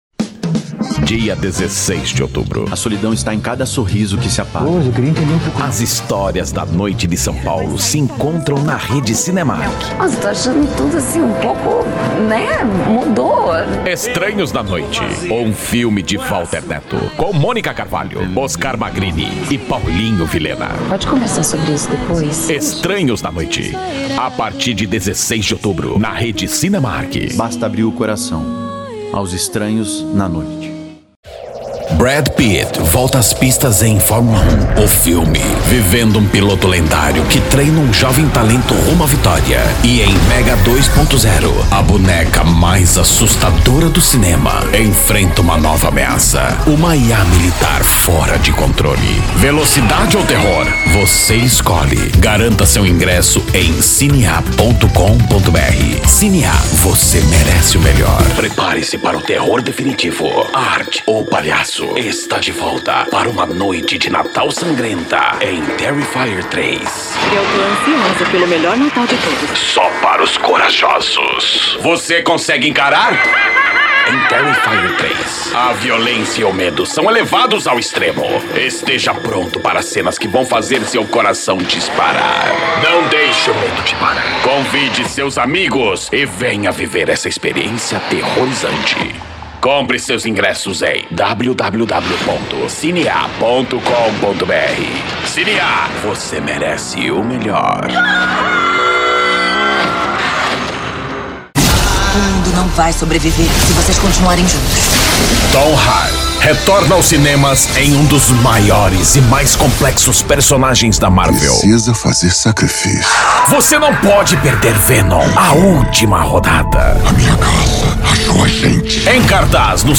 DEMONSTRATIVO CINEMAS, FILMES E ARTES VISUAIS:
Impacto